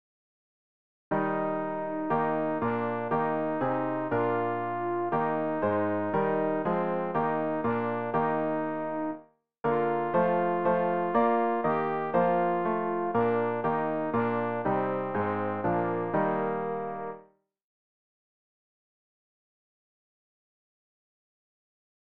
rg-774-christus-der-ist-mein-leben-alt.mp3